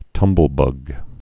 (tŭmbəl-bŭg)